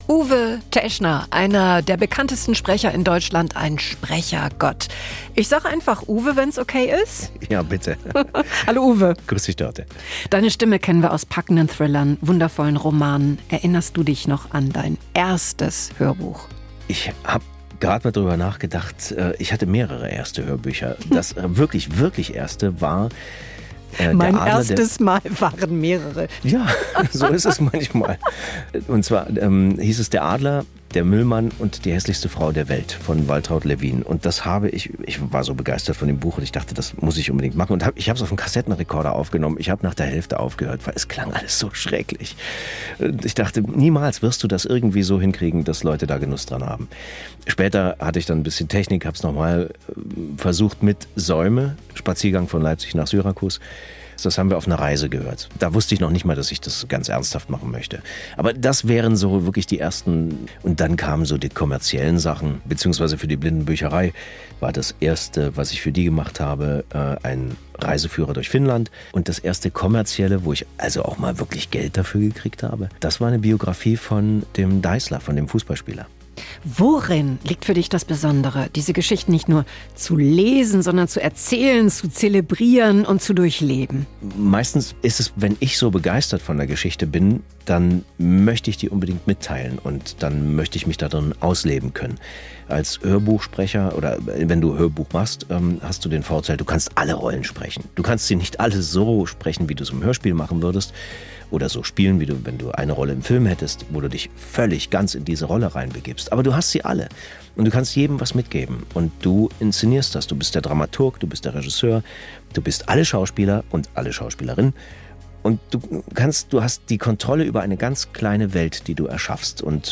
Vor seinem Auftritt hat uns der bekannte Hörbuch- und Synchronsprecher ein Interview gegeben.